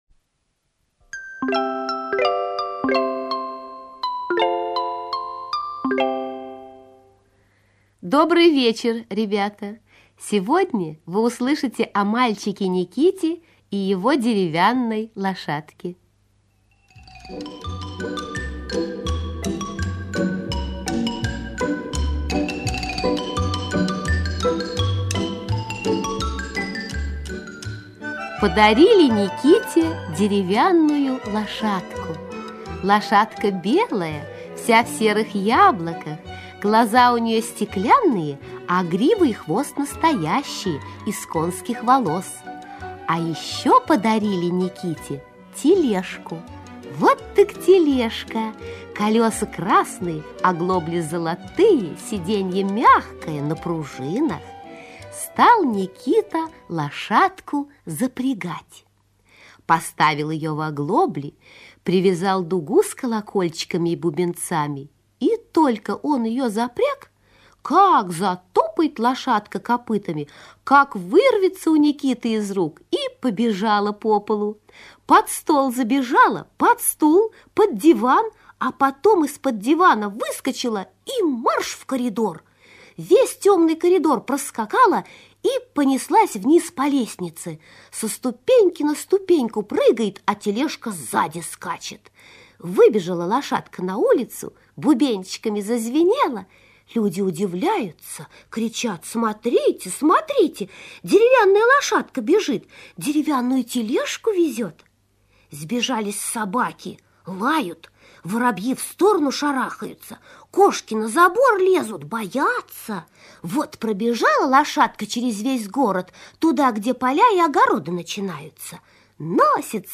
Аудиорассказ «Как лошадка зверей катала»
Текст читает Клара Румянова.